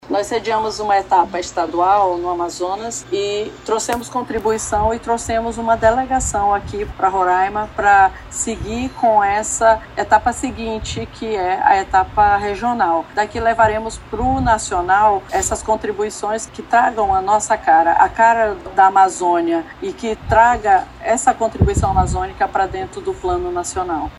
A secretária executiva de Direitos da Criança e do Adolescente, Rosalina Lôbo, destaca a importância de considerar as peculiaridades da região amazônica ao criar políticas públicas para as crianças e adolescentes.